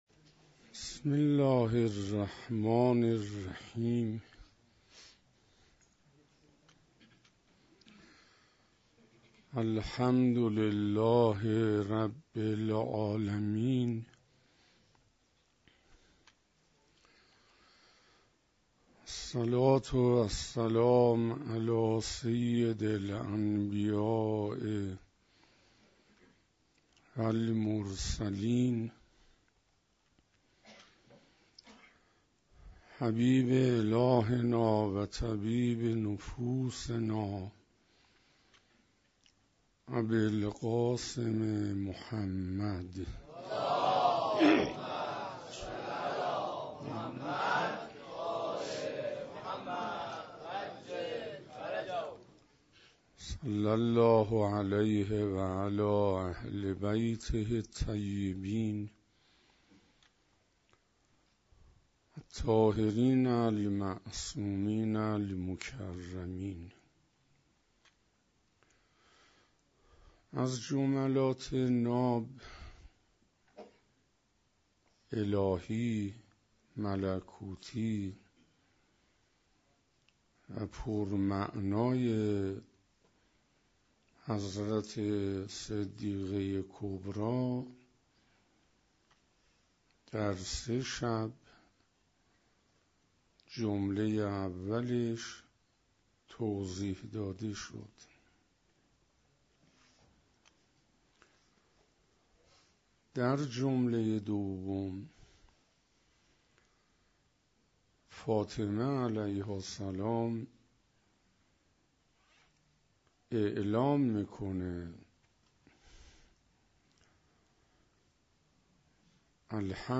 حسینیه اهل بیت (گلپور) - شب چهارم - مقام ناشناختهٔ حضرت زهرا(س)